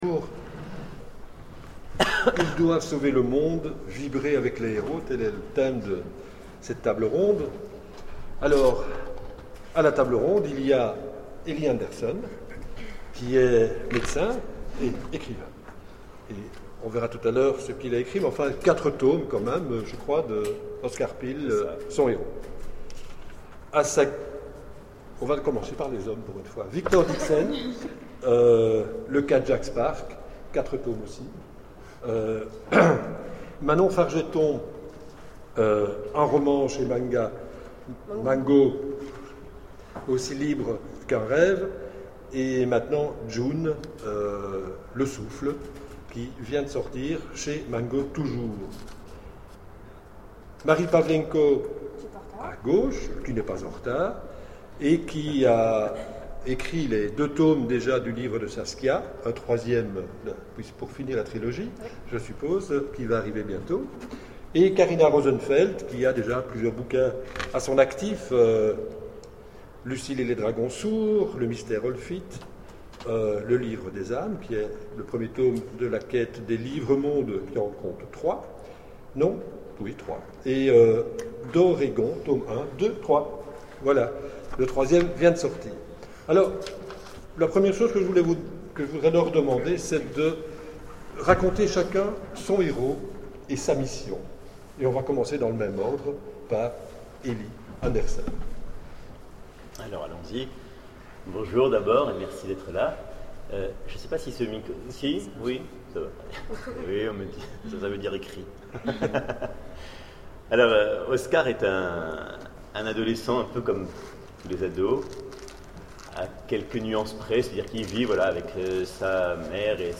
Imaginales 2012 : Conférence Ils doivent sauver le monde
Conférence